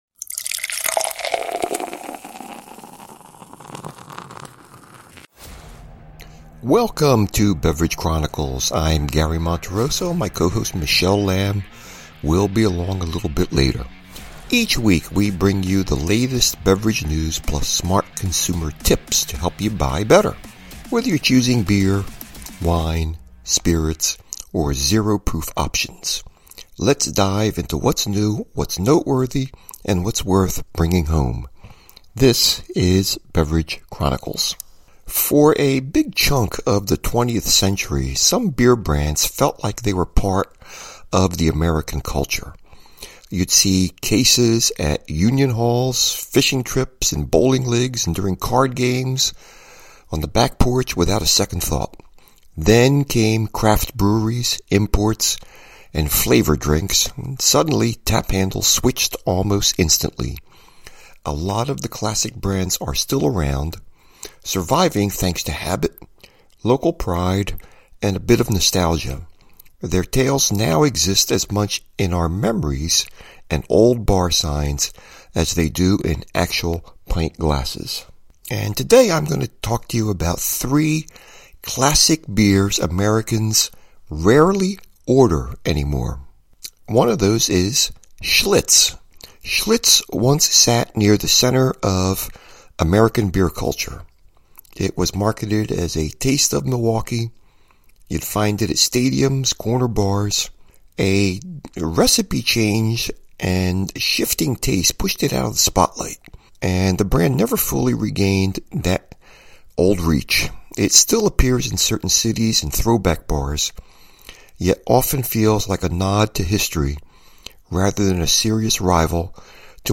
Talk Show Episode
From the PHiladelphia Wine Festival